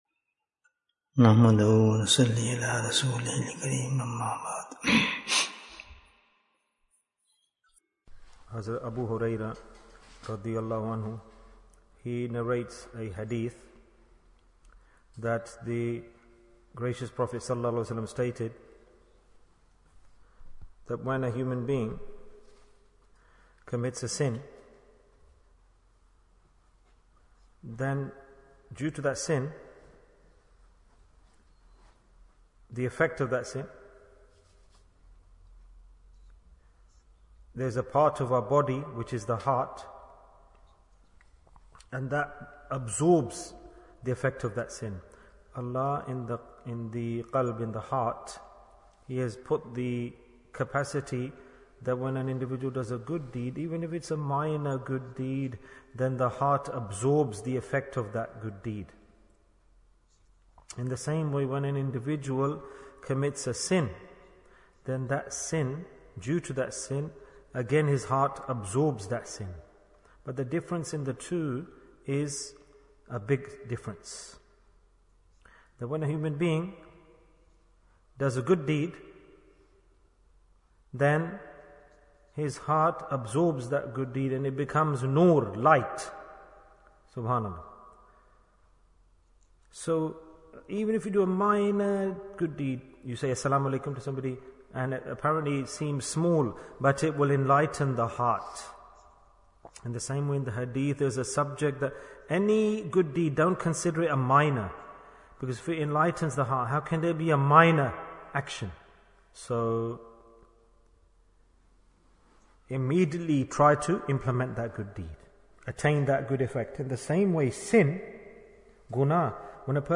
Refrain From Sin Bayan, 15 minutes14th May, 2023